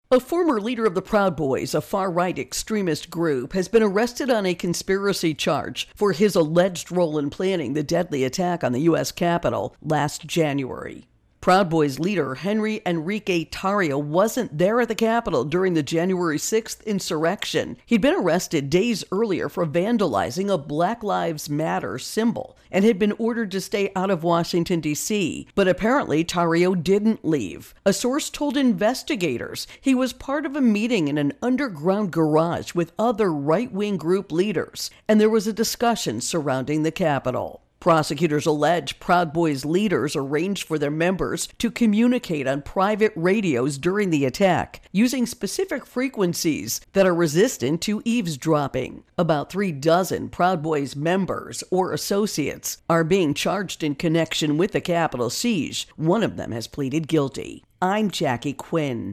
Capitol Riot Proud Boys Intro and Voicer